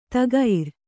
♪ tagair